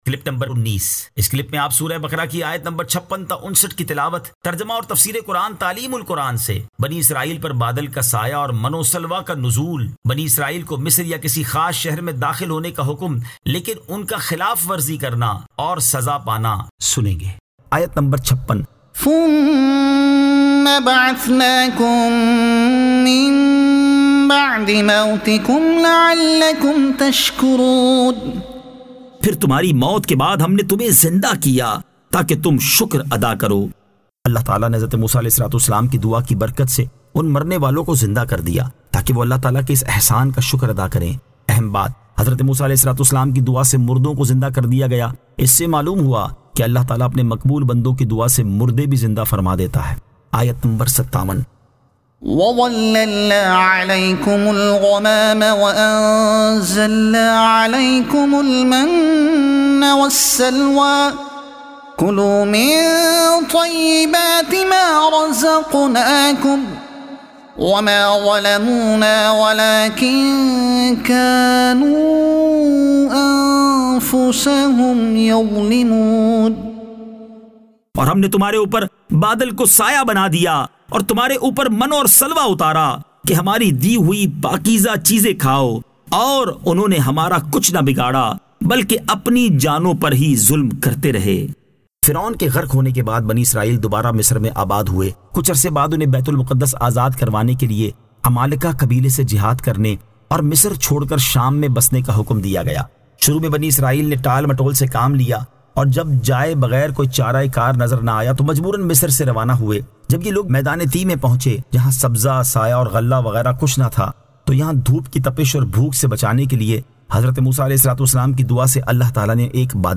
Surah Al-Baqara Ayat 56 To 59 Tilawat , Tarjuma , Tafseer e Taleem ul Quran